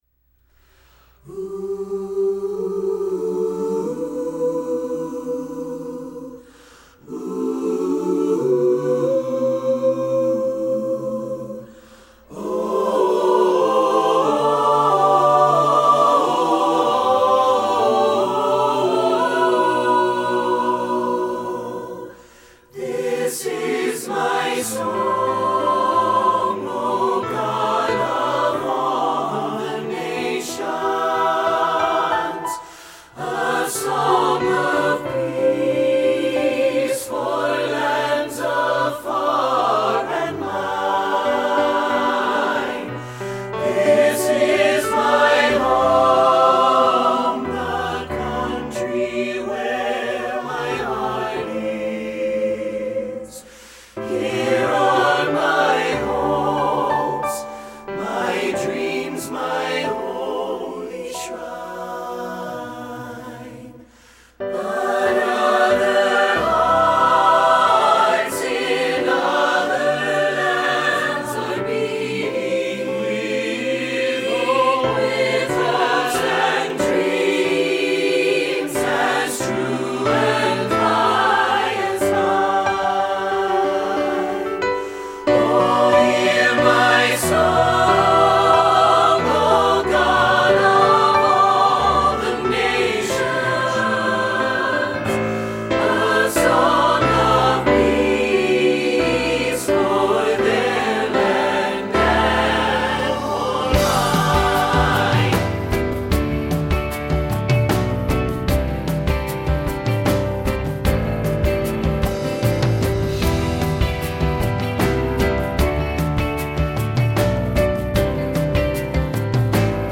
pop choral